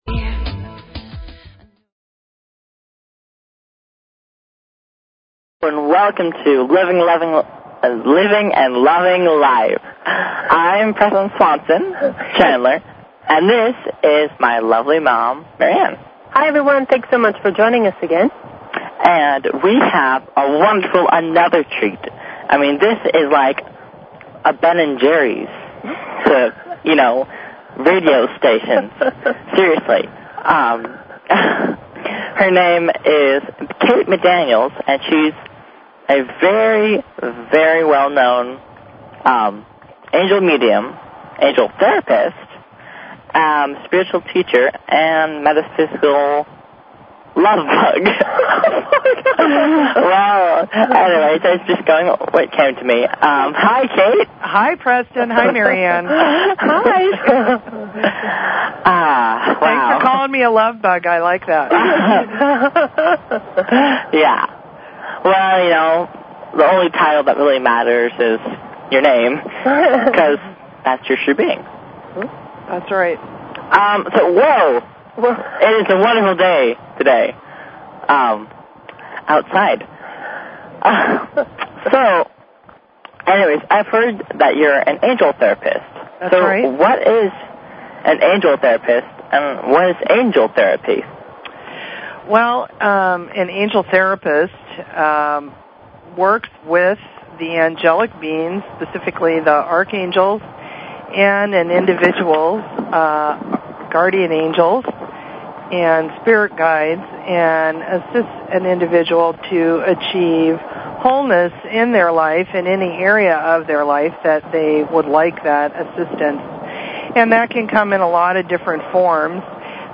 Talk Show Episode, Audio Podcast, Living_and_Loving_Life and Courtesy of BBS Radio on , show guests , about , categorized as